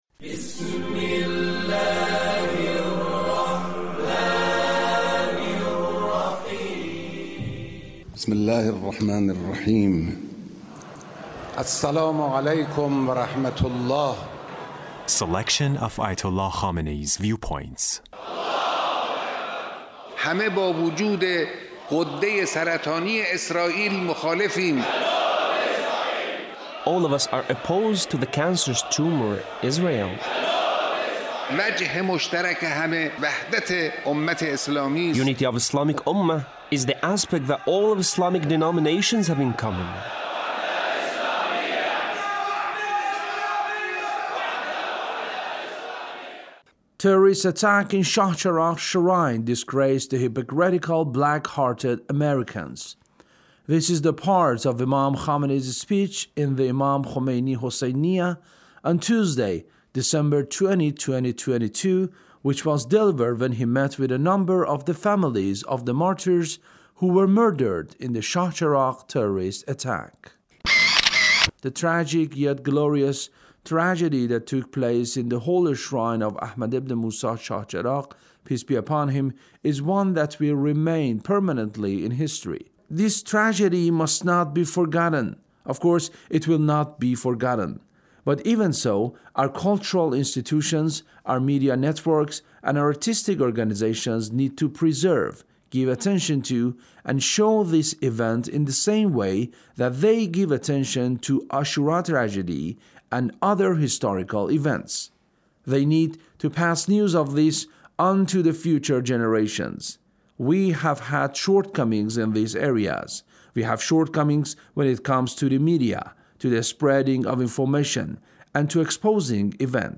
Leader's Speech about Chahcheragh Terrorist Attack